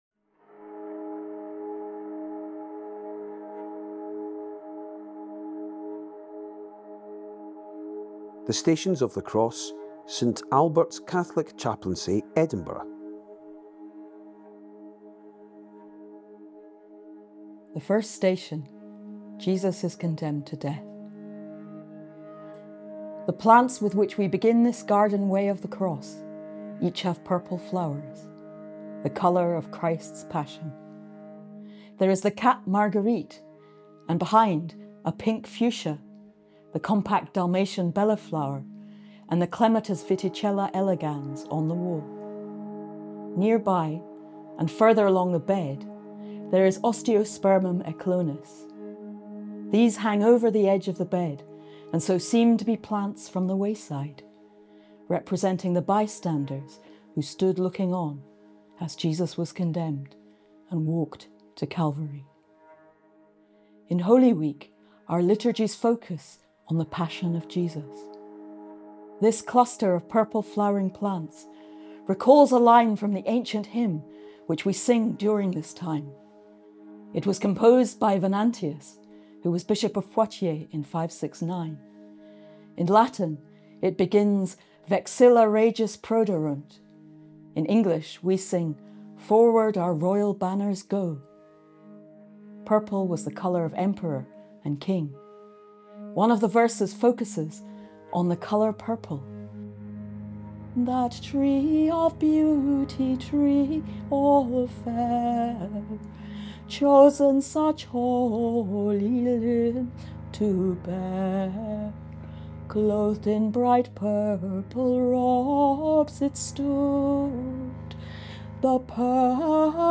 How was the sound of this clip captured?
mp3 recording of The Stations of The Cross, from St Albert's Catholic Chaplaincy, Edinburgh